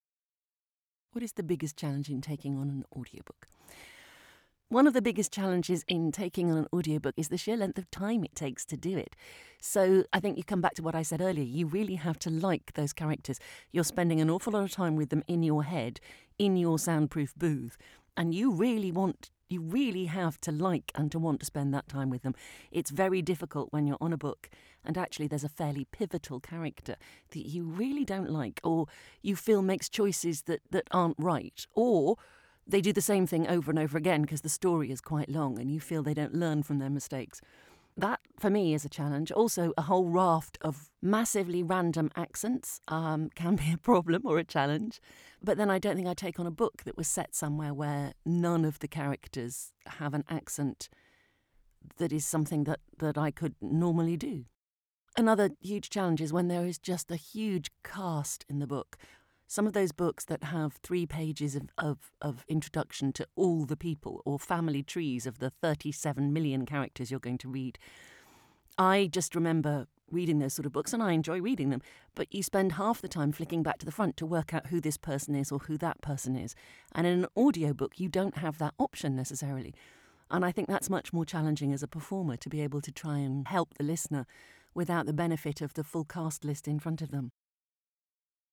I hope you dug listening to the interview as much as I did and will take a listen to the audiobook.